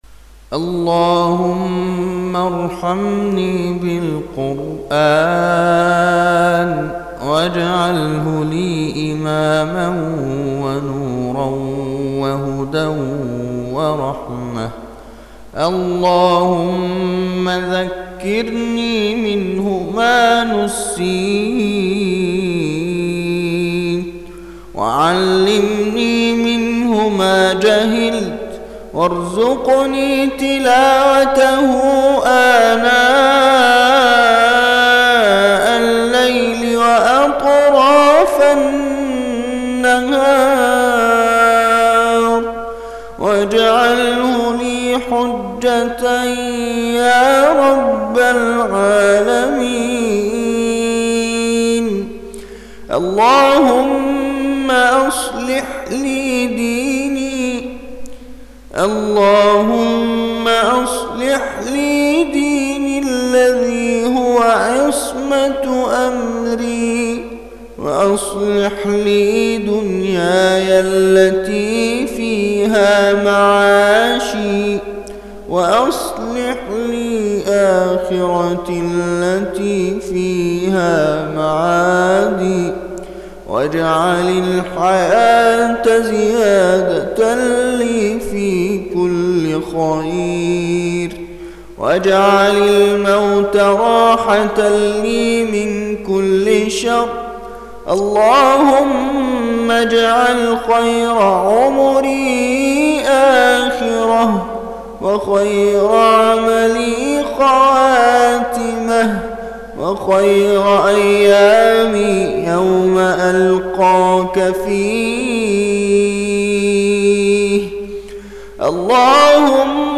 أدعية وأذكار
تسجيل لدعاء ختم القرآن الكريم